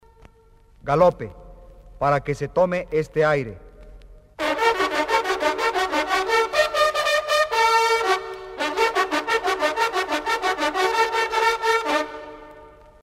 TOQUES MILITARES CON TROMPETA PARA EL ARMA DE CABALLERIA
galope.mp3